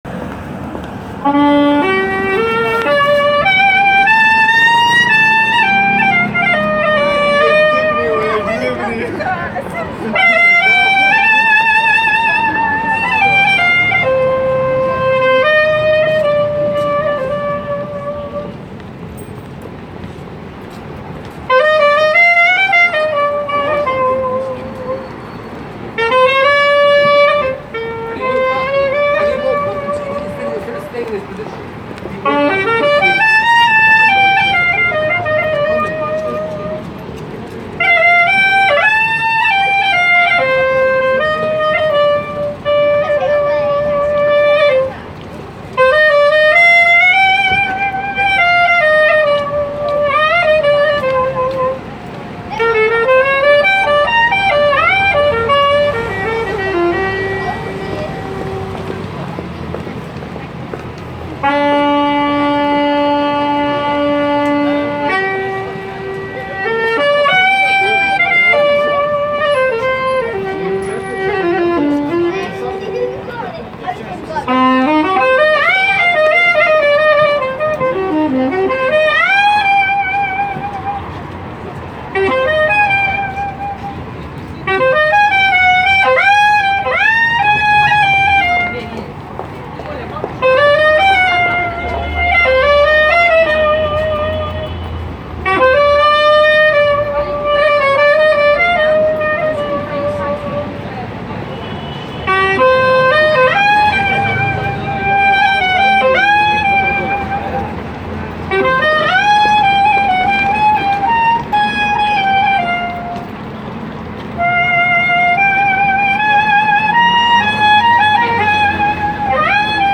➤ Take two minutes to raise your spirits with this busking sax player
Busker, jazz, saxophone, high street
Our local saxophone busker: turning heads on the high street
Let this old guy’s melancholy yet hopeful strains from his saxophone turn the windmills of your mind. . .